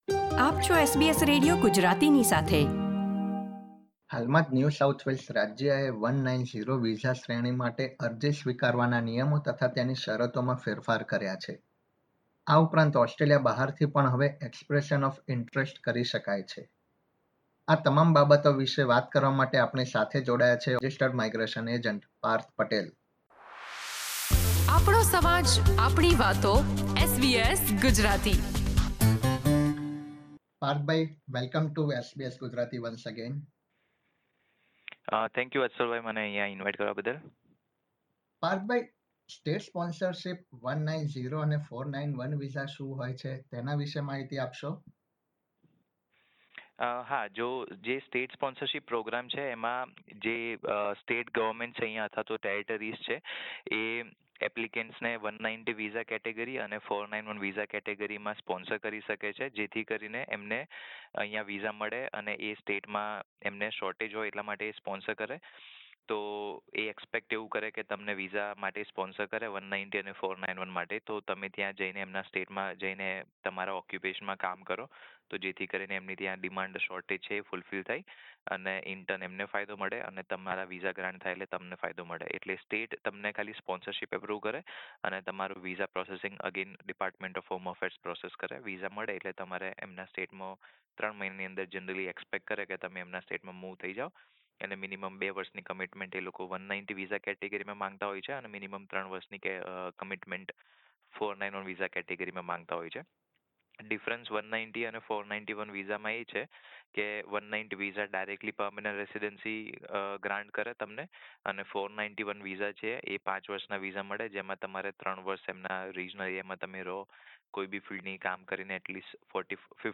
** ઇન્ટરવ્યૂમાં આપવામાં આવેલી માહિતી સામાન્ય સંજોગો આધારિત છે.